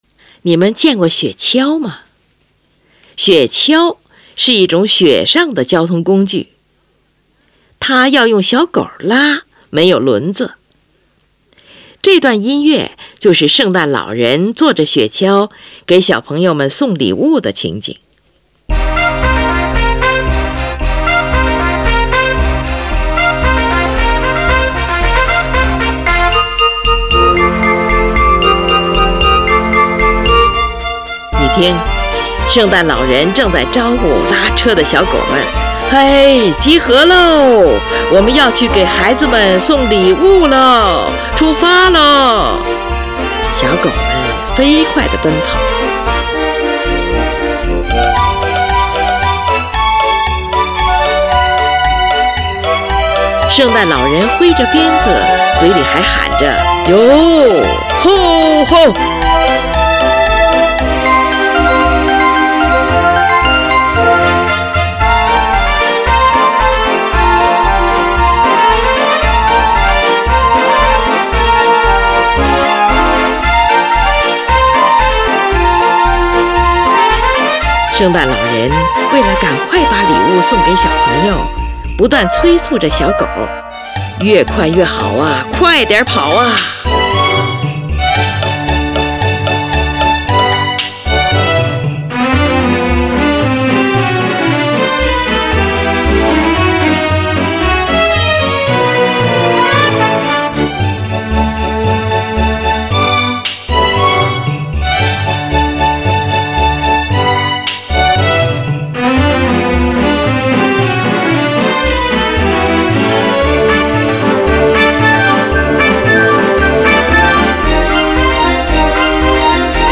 这首短小的乐曲共分为三个部分，即A-B-A。